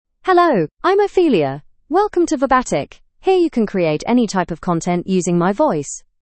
FemaleEnglish (United Kingdom)
OpheliaFemale English AI voice
Ophelia is a female AI voice for English (United Kingdom).
Voice sample
Listen to Ophelia's female English voice.
Ophelia delivers clear pronunciation with authentic United Kingdom English intonation, making your content sound professionally produced.